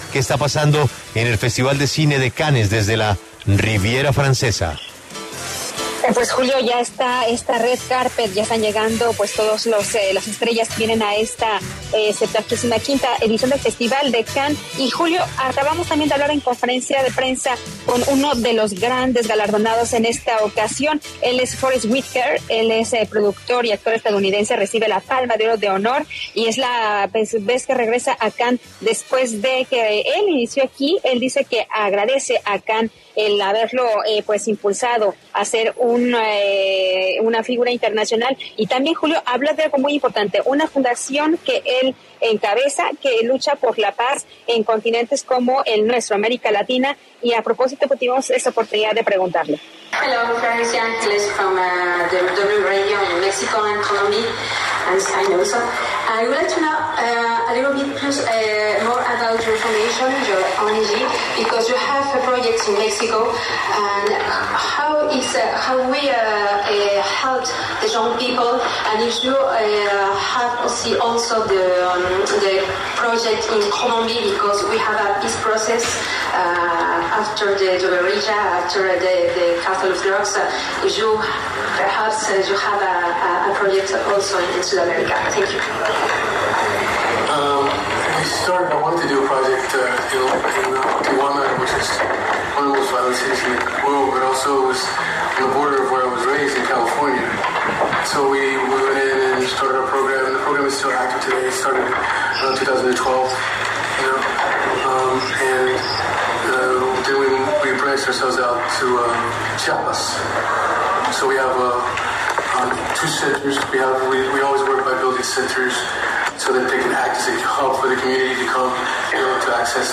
Desde el Festival de Cannes, La W dialogó con Forest Whitaker sobre la labor social del actor.